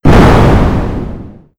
scsm_explosion1w.wav